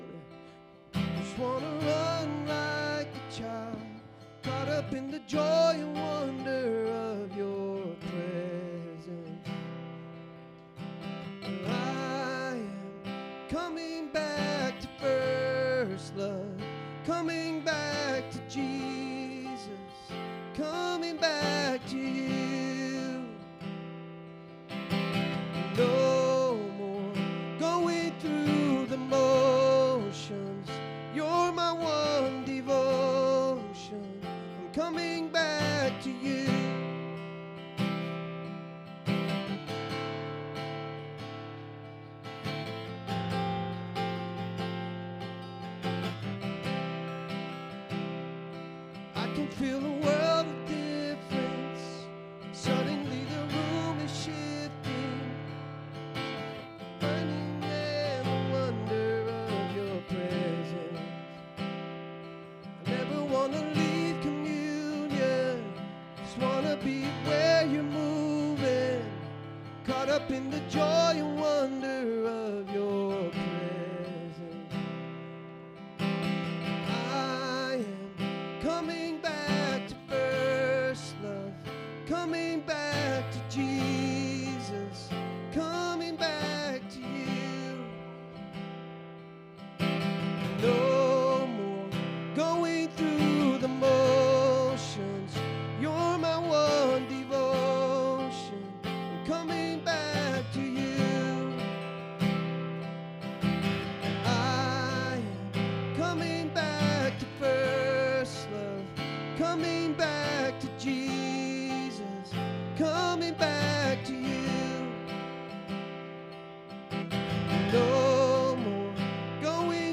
98 Campus | Hope on the Beach Church